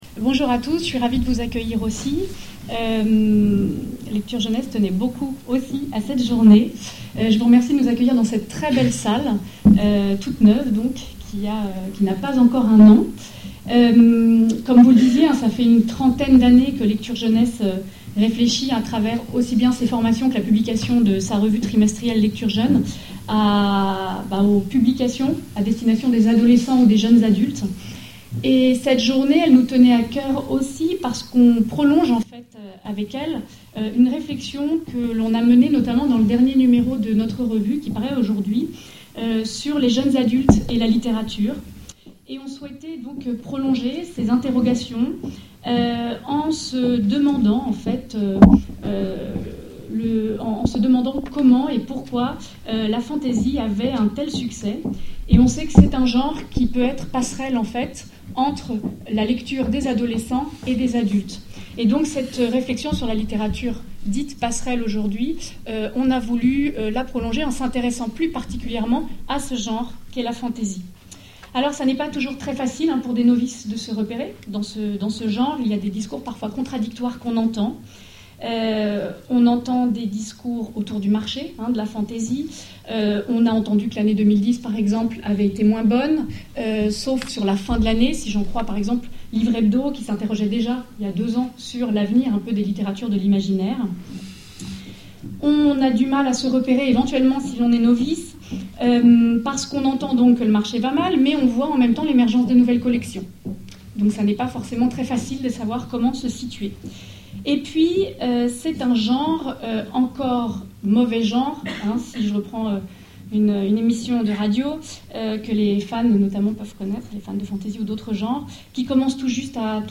En mars dernier, l’association organisait une journée d’étude autour de la fantasy.